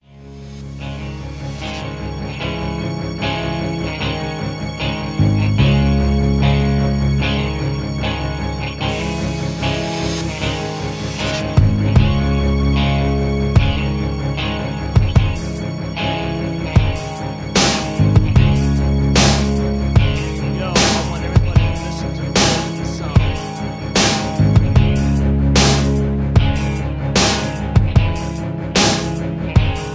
rap, r and b, hip hop